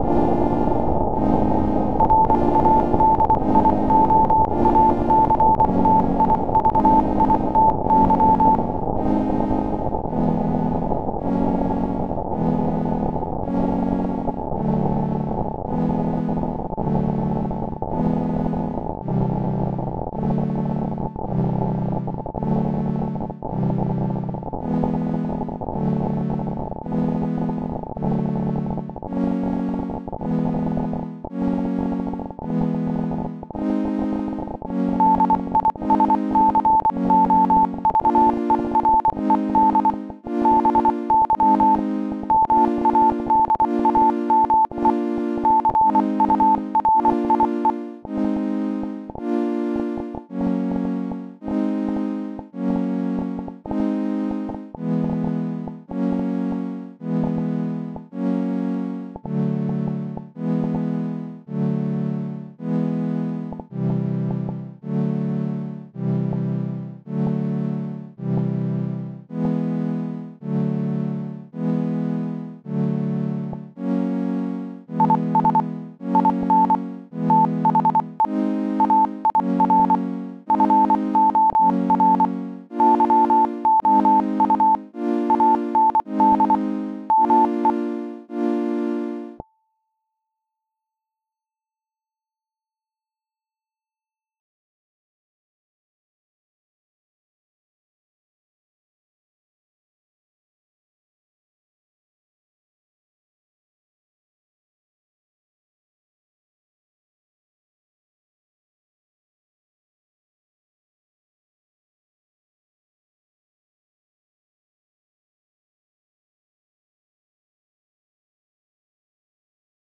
• example/radioactivity.f90: you can hear the simulation of the radioactive decay of a population of atoms heard with a Geiger counter, with chords in a whole tone scale and a Morse code message (src/morse_code.f90 is offering basic Morse code support).
radioactivity.ogg